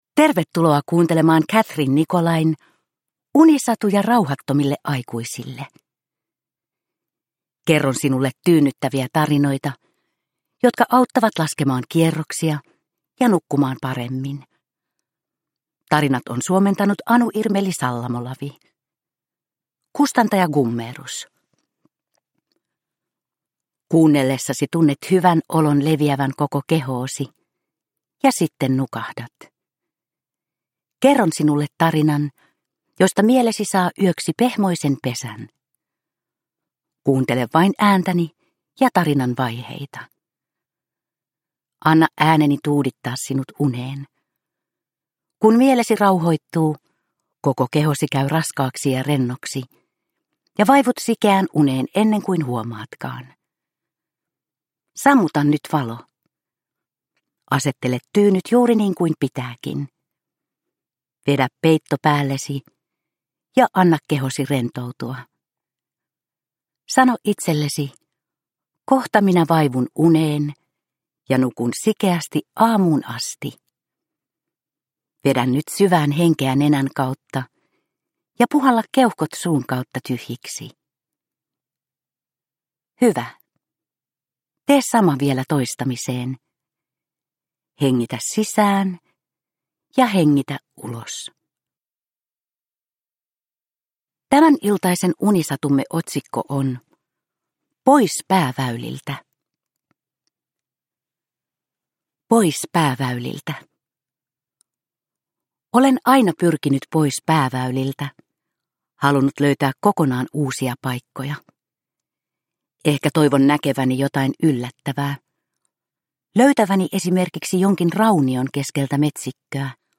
Unisatuja rauhattomille aikuisille 39 - Pois pääväyliltä – Ljudbok – Laddas ner